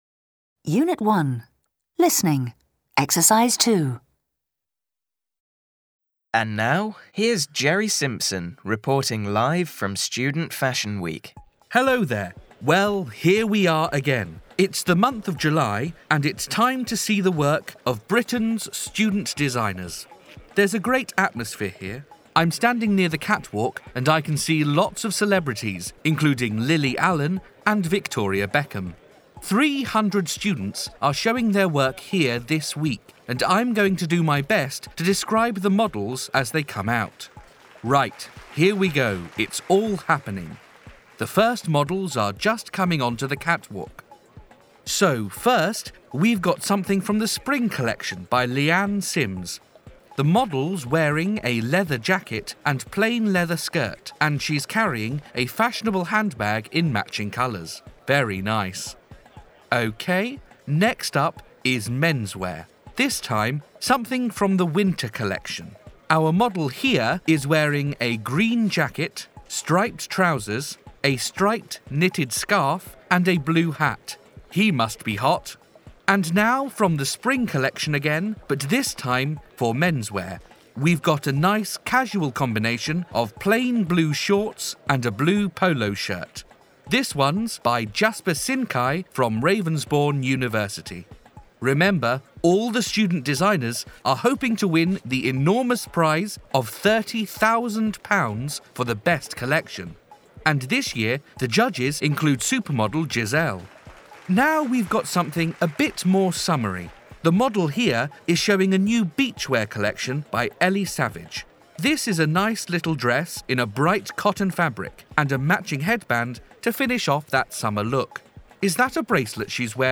Listen to a radio report.